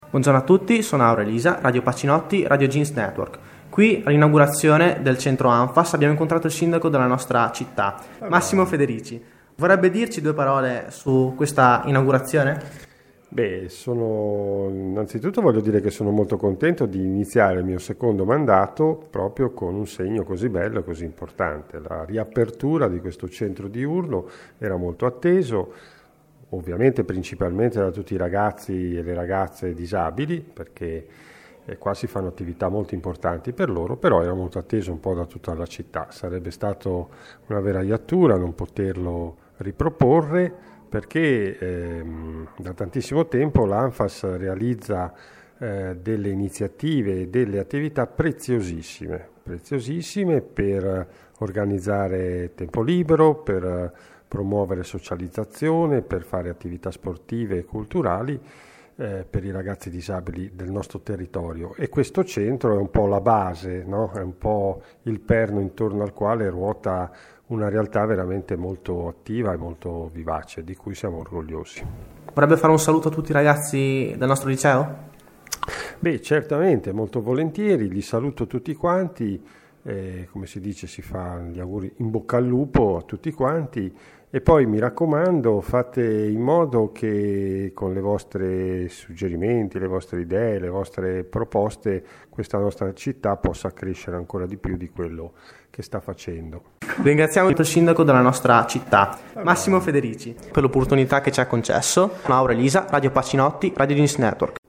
Intervista a MAssimo Federici
play_circle_filled Intervista a MAssimo Federici Radioweb Pacinotti Sindaco della Spezia intervista del 13/06/2012 Si è svolta nei locali dell'ANFFAS la cerimonia di riapertura dopo le traversie economiche che avevano costretto ad eliminare il supporto ai disabili, qui abbiamo incontrato dirigenti e personalità politiche e religiose.